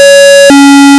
computer_alarm1.wav